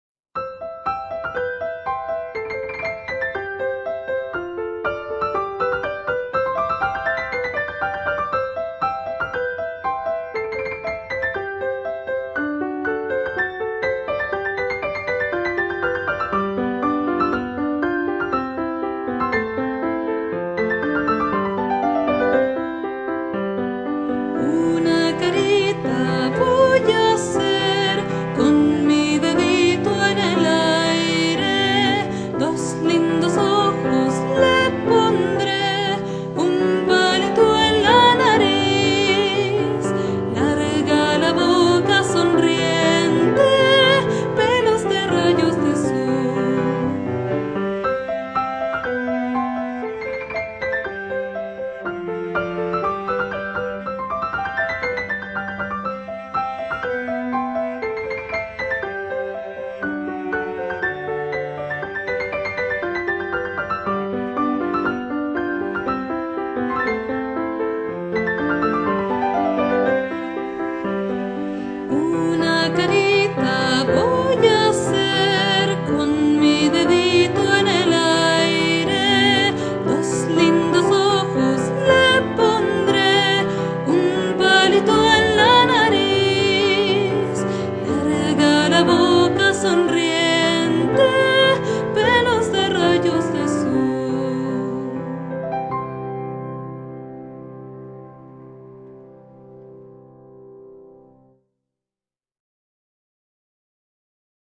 Música infantil
Canciones